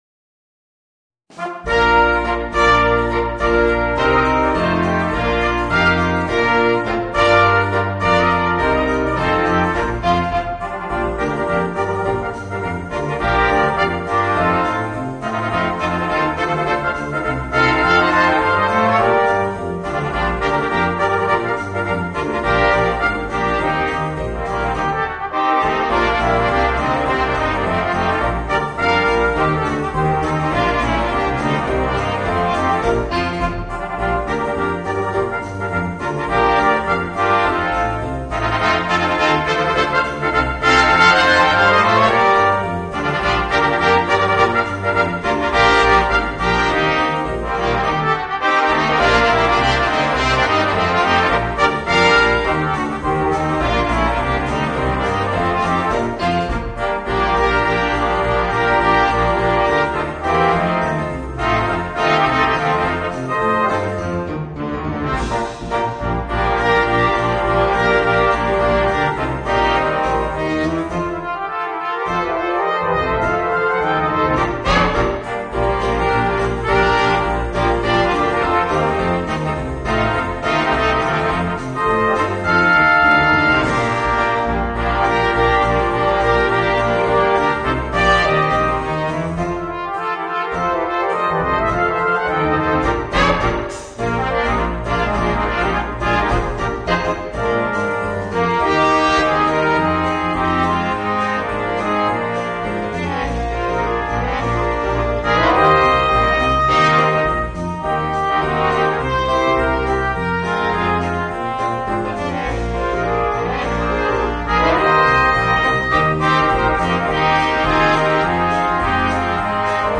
Voicing: 2 Trumpets, Horn and 2 Trombones